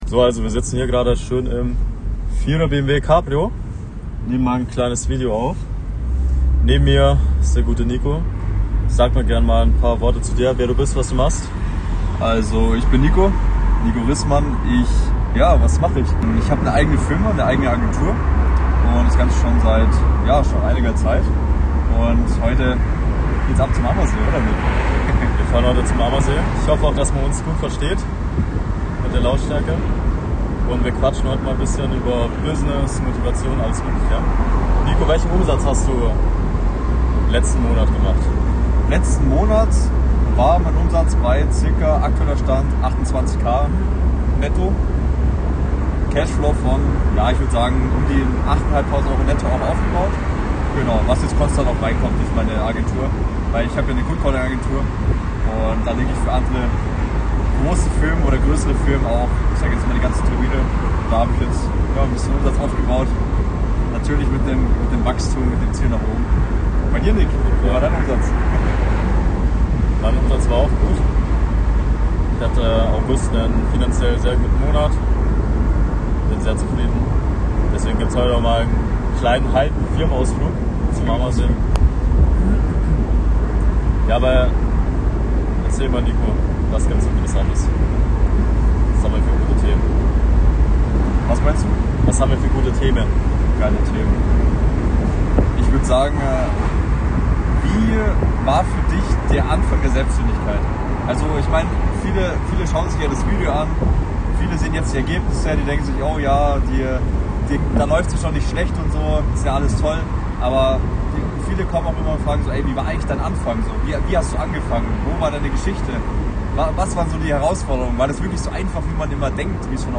#23 Business Talk im BMW Cabrio: Wie wir mit 23 sechsstellig wurden (ohne Glück) ~ Die Fitpreneure - Mehr Erfolg als Fitnesscoach Podcast
Auto-Podcast im BMW: Wir teilen, wie wir mit 23 sechsstellig wurden, welche Fehler uns fast gekillt hätten und welche Hebel alles verändert haben.